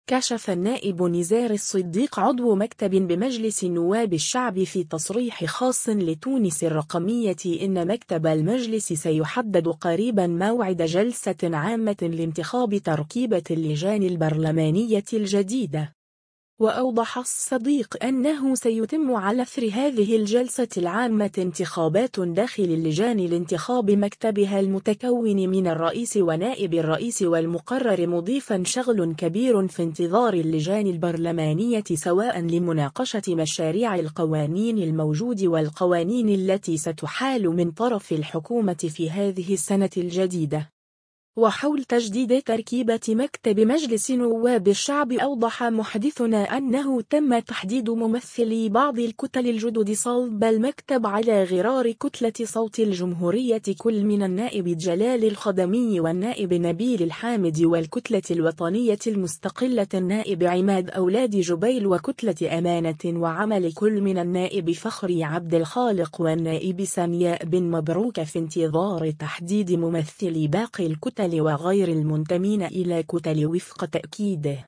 كشف النائب نزار الصديق عضو مكتب بمجلس نواب الشعب في تصريح خاص لـ”تونس الرقمية” ان مكتب المجلس سيحدد قريبا موعد جلسة عامة لانتخاب تركيبة اللجان البرلمانية الجديدة.